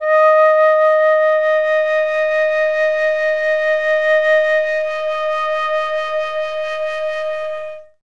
51c-flt06-D#4.wav